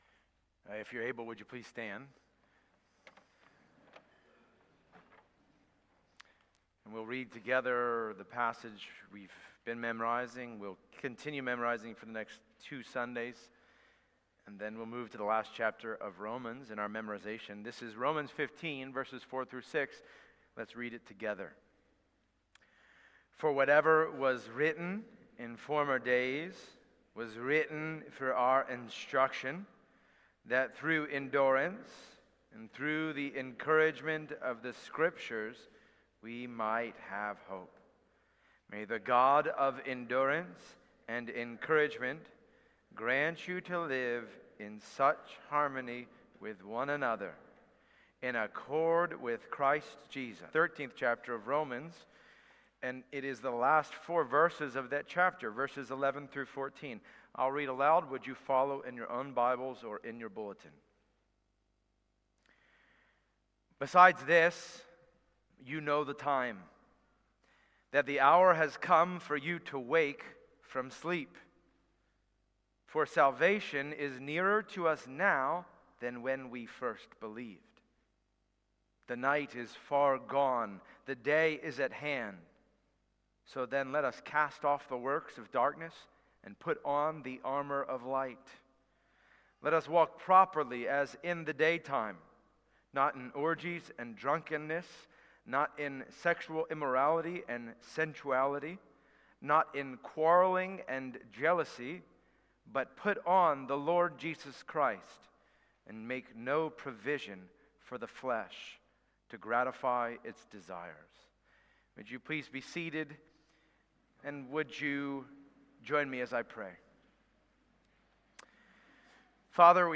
Passage: Romans 13:11-14 Service Type: Sunday Morning Download Files Bulletin « The Authentic